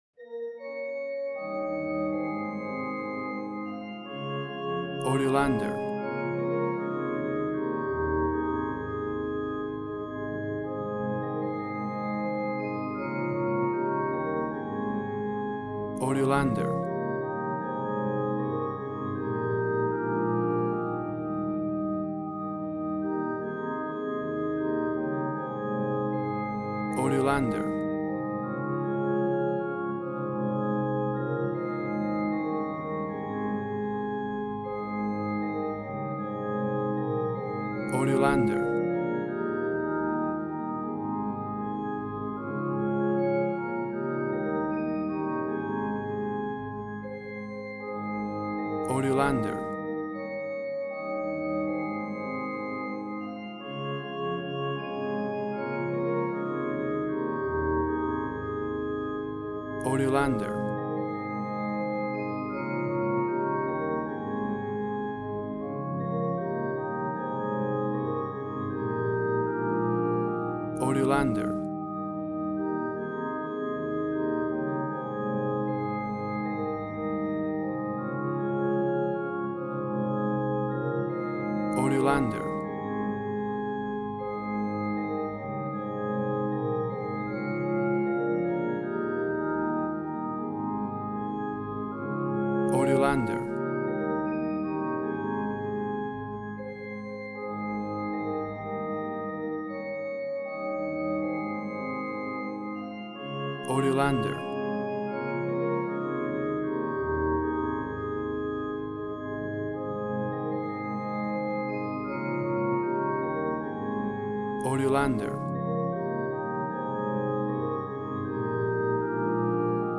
A traditional and classical version
Tempo (BPM): 77